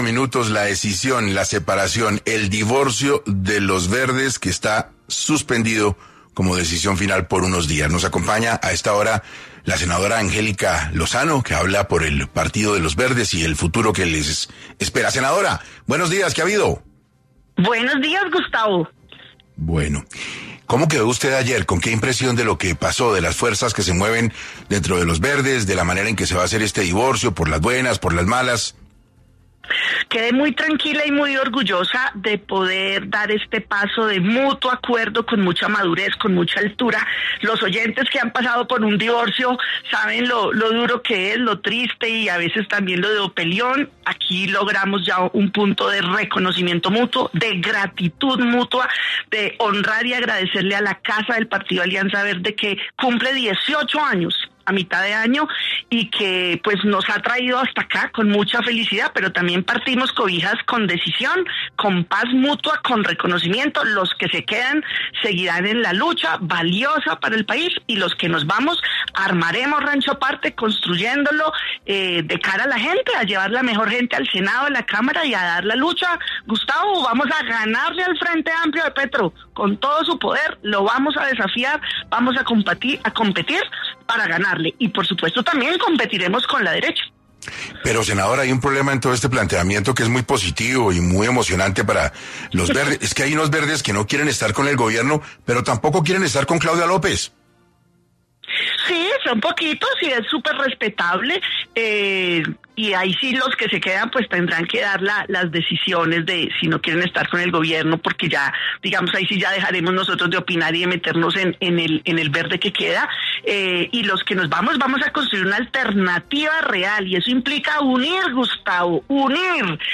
En 6AM de Caracol Radio, la senadora Angélica Lozano confirmó que el sector que no respalda al presidente Gustavo Petro ni a la derecha emprenderá un camino independiente, con el objetivo de conformar un nuevo bloque político.